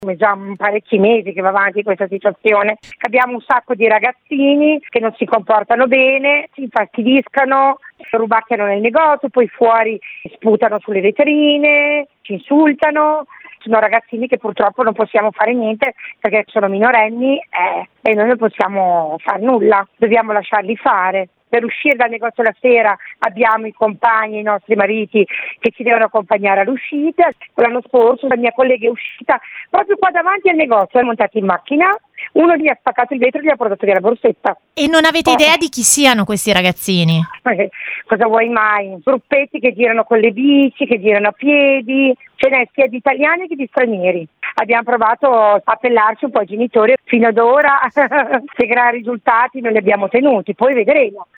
Una dipendente che abbiamo raggiunto al telefono